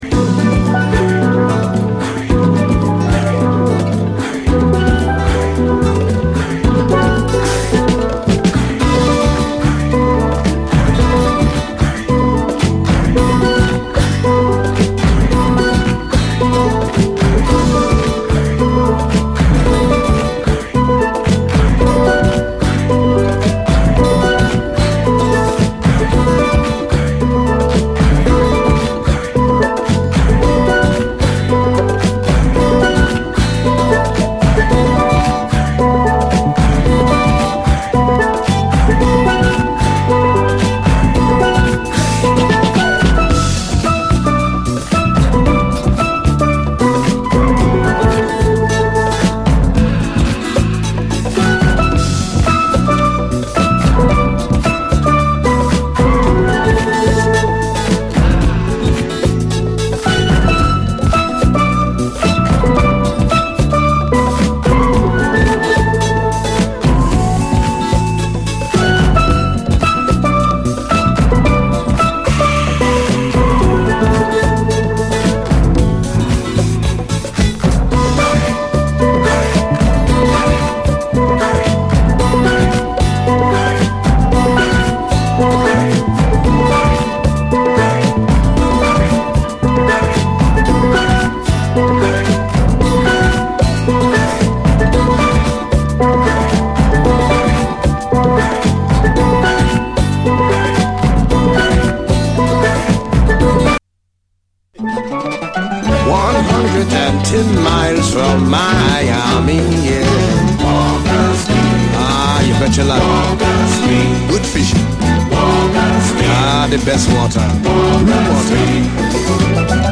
SOUL, 70's～ SOUL, CARIBBEAN
リラクシンでカリビアンな名作！